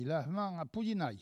Langue Maraîchin
locutions vernaculaires